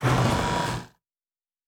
pgs/Assets/Audio/Sci-Fi Sounds/Mechanical/Servo Big 6_1.wav at 7452e70b8c5ad2f7daae623e1a952eb18c9caab4
Servo Big 6_1.wav